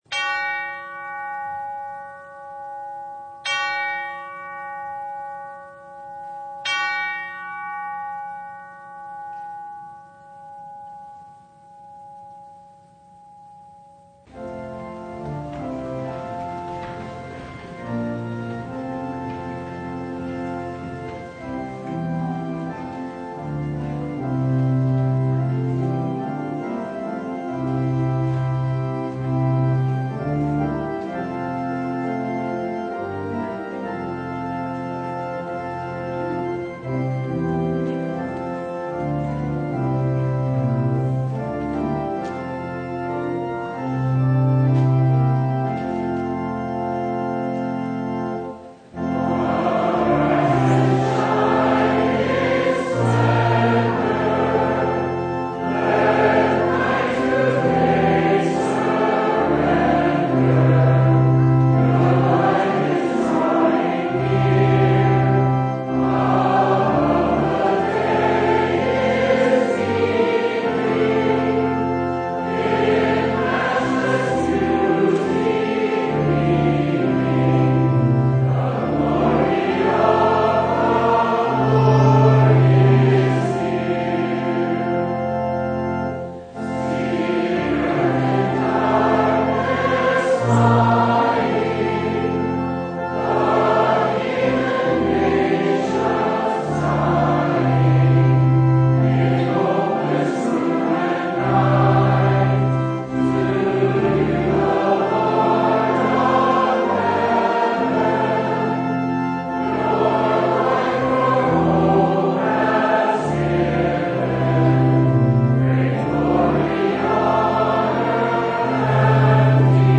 September 5, 2021 The Fifth Sunday in Martyrs’ Tide (audio recording) Preacher: Visiting Pastor Passage: Mark 7:31-37 Service Type: Sunday God over skype?